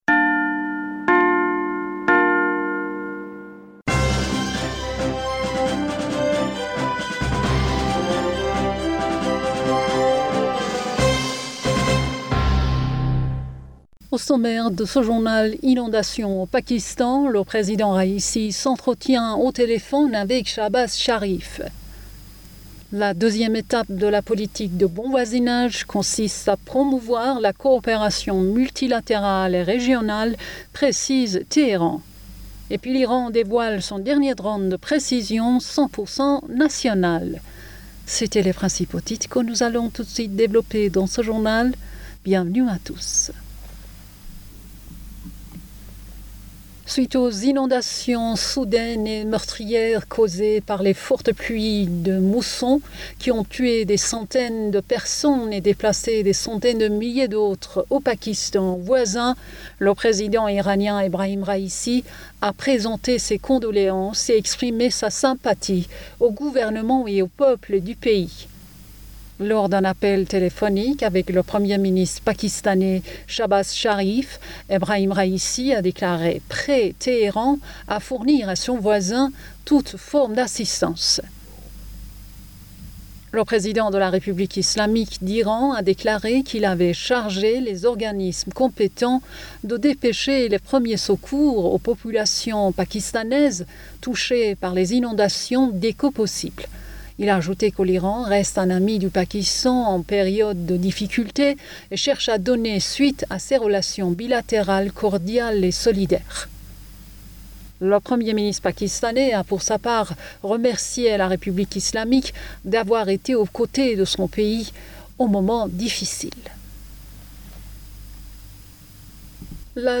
Bulletin d'information Du 28 Aoùt